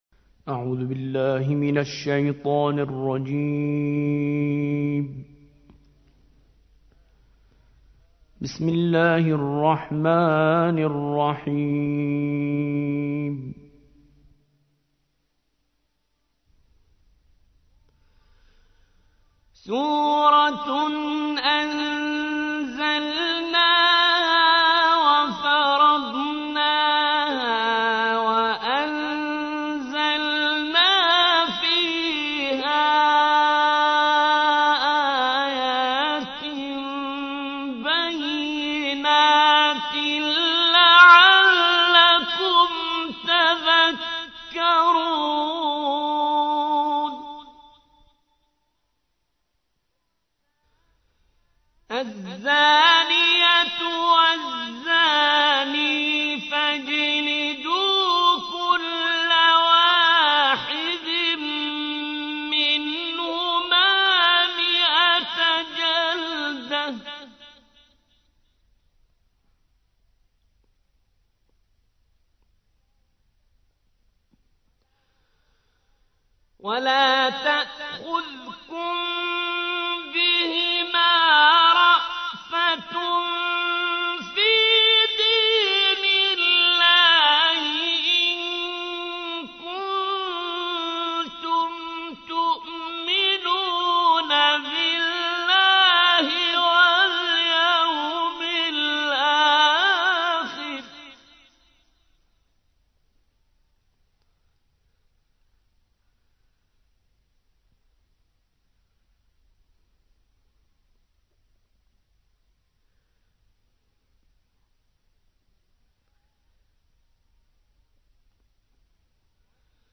24. سورة النور / القارئ